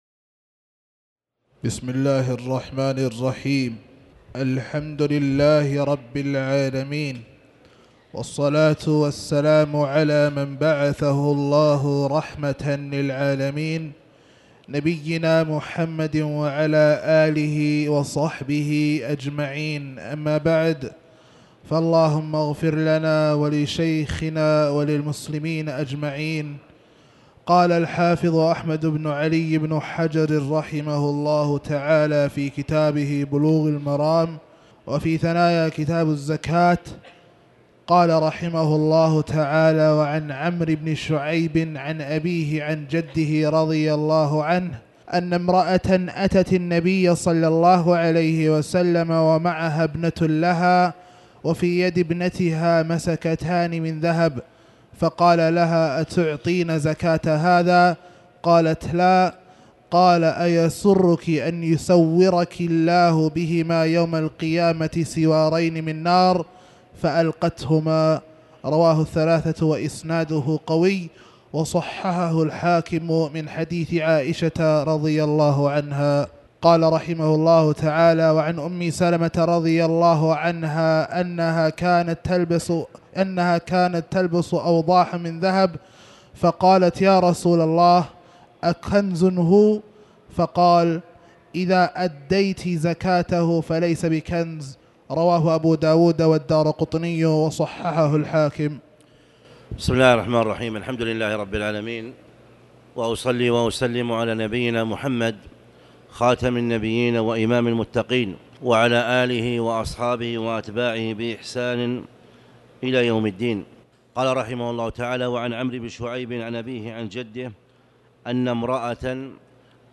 تاريخ النشر ٢٩ جمادى الآخرة ١٤٣٩ هـ المكان: المسجد الحرام الشيخ